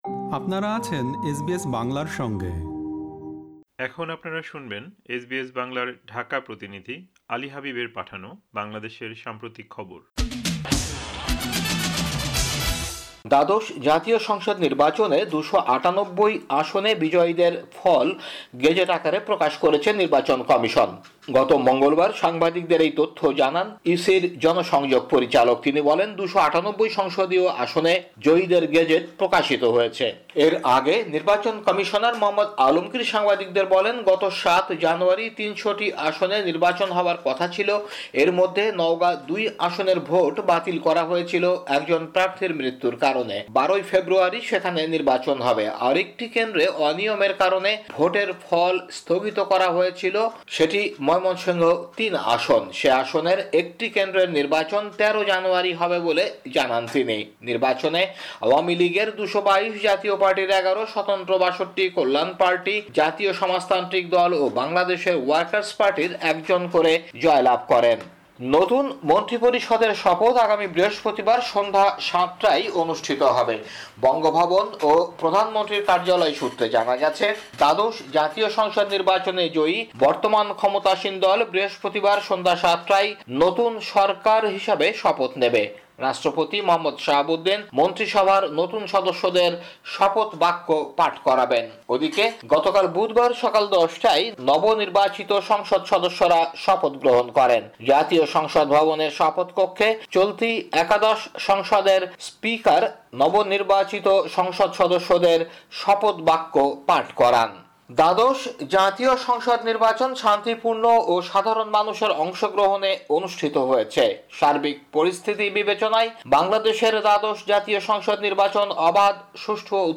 বাংলাদেশের সাম্প্রতিক খবর: ১১ জানুয়ারি, ২০২৪